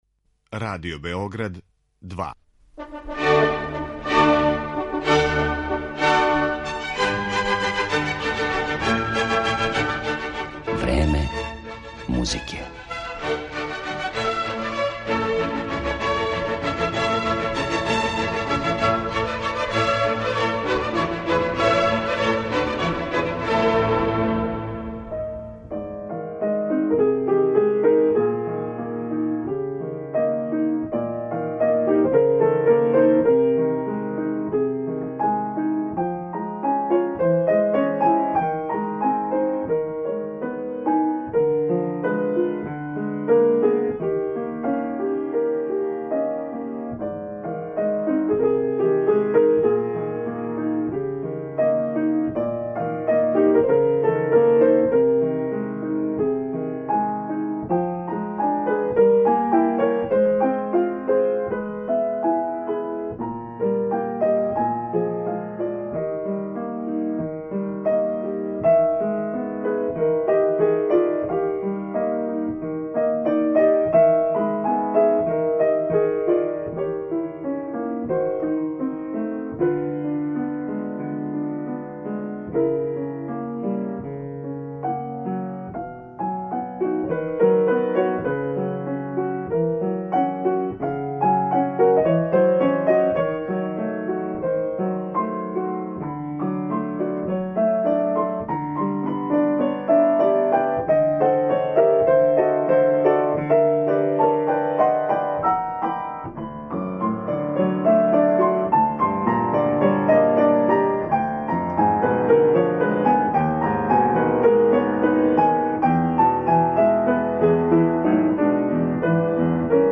У данашњој емисији Време музике преносимо делове предавања са форума Музиколошког института САНУ.